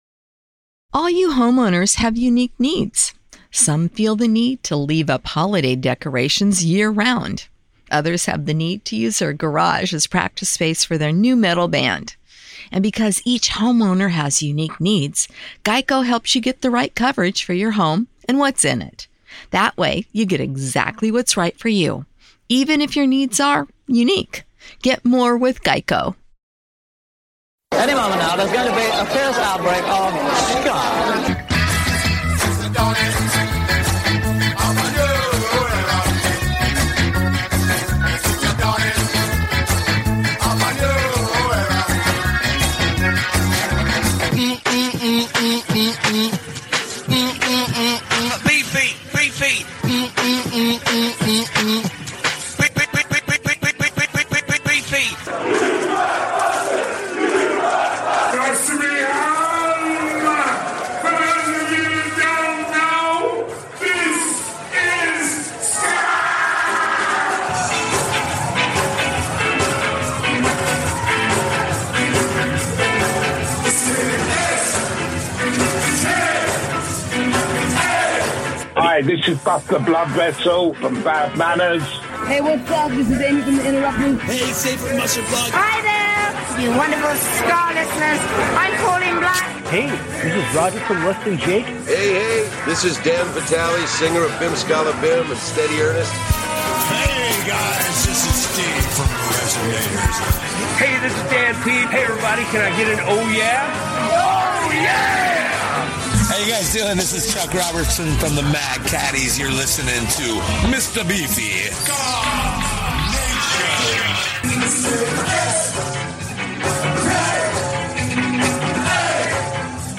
**THE WORLD'S #1 SKA SHOW - SKA NATION RADIO - FOR YOUR LISTENING PLEASURE **